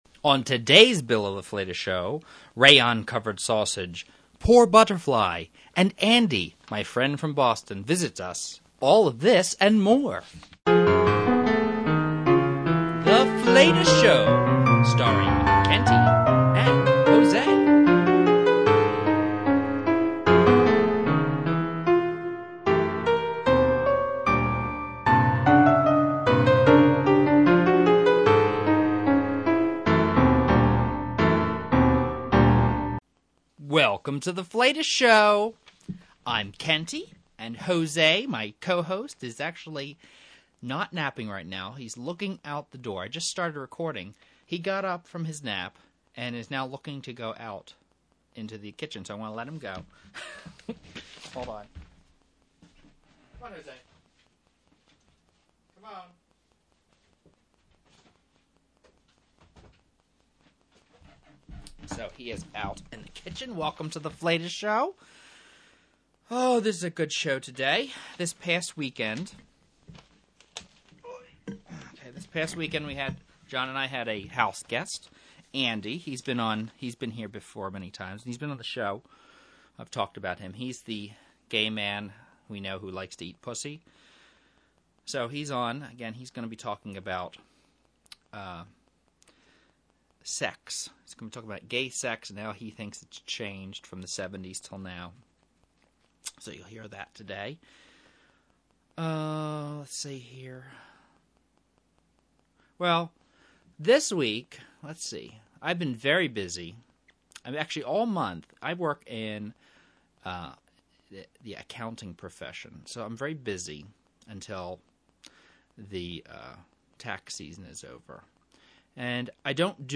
The Flatus Show is a weekly variety podcast in vaudeville style.